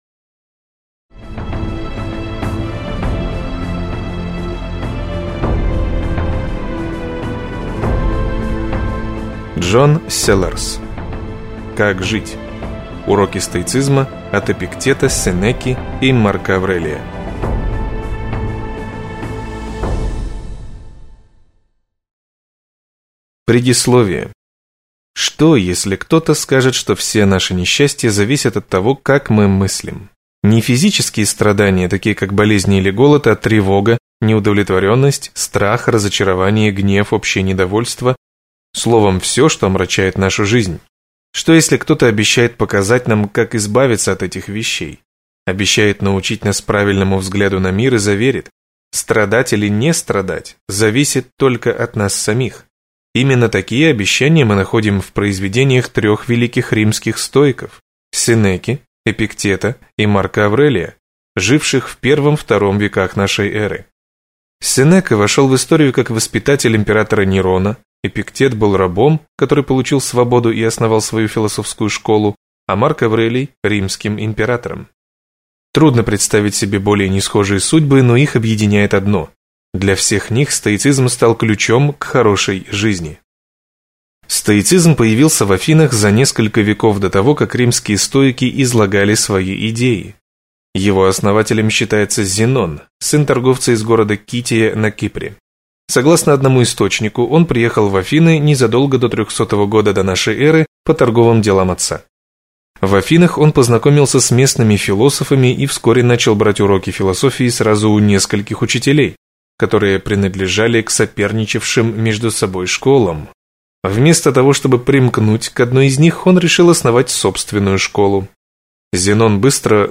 Аудиокнига Как жить. Уроки стоицизма от Эпиктета, Сенеки и Марка Аврелия | Библиотека аудиокниг